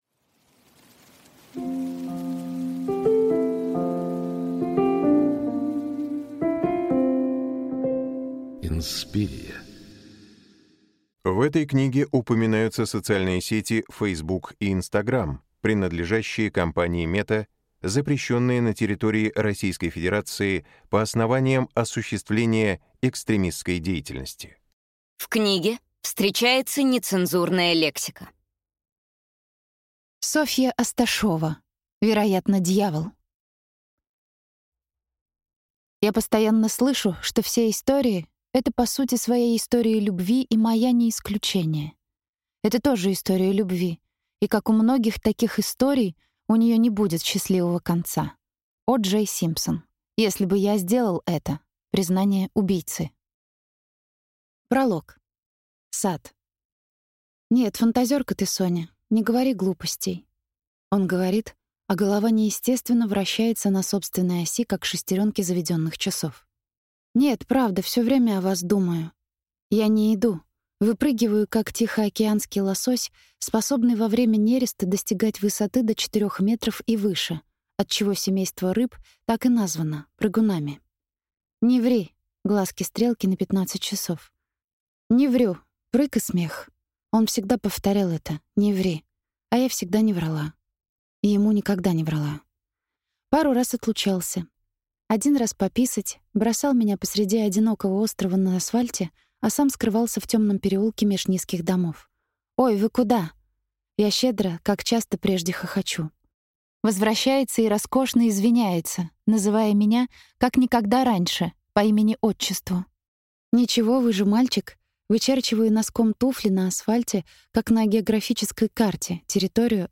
Аудиокнига Вероятно, дьявол | Библиотека аудиокниг